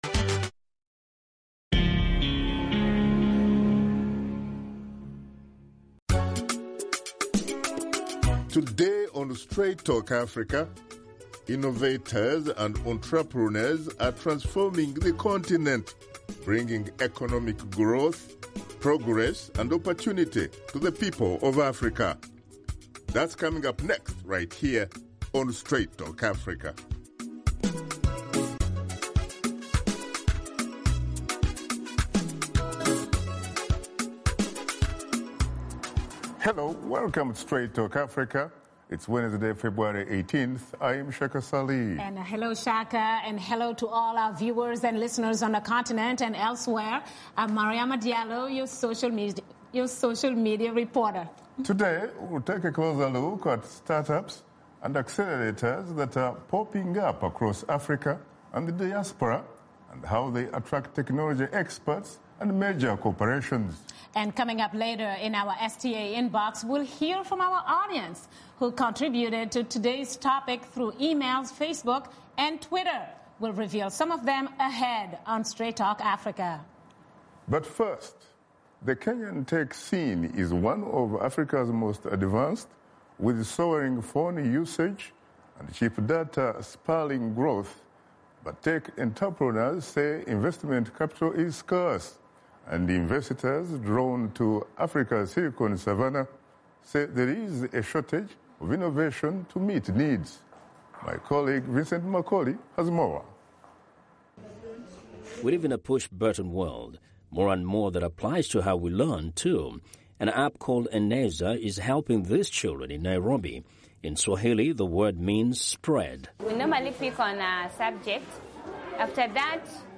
One on One Interview
Washington Studio Guests